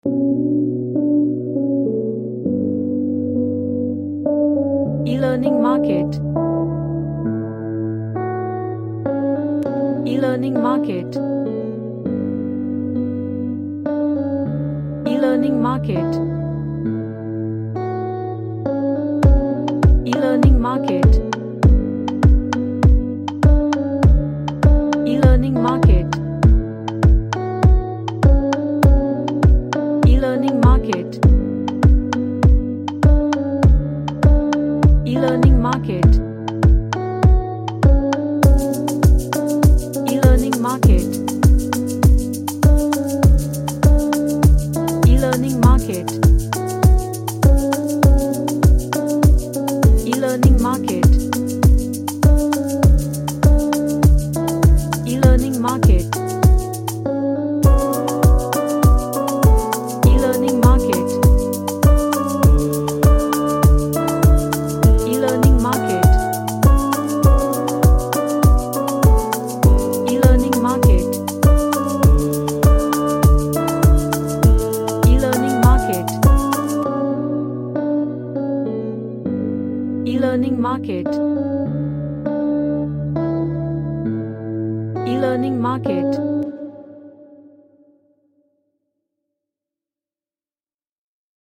A dance track with chill vibes
Chill OutPlayful